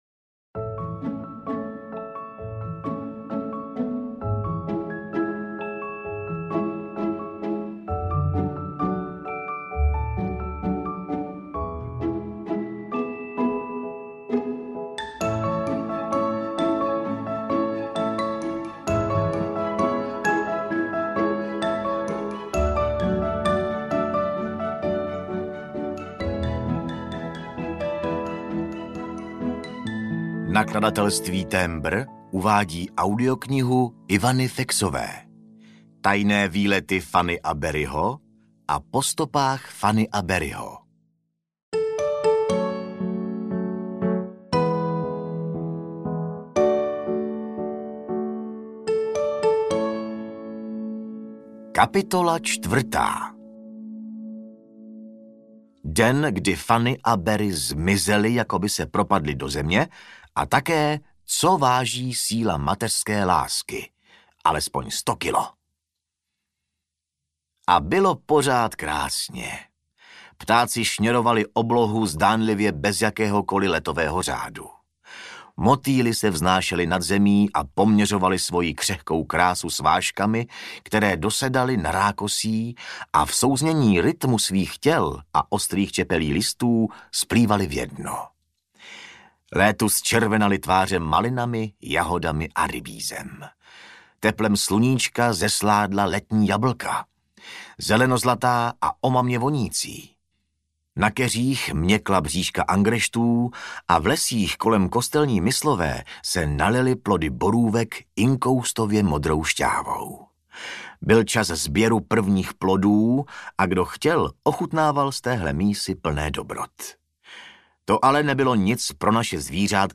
Tajné výlety Fany a Beryho a Po stopách Fany a Beryho audiokniha
Ukázka z knihy